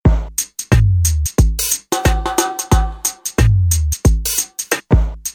reggae drumloops soundbank 2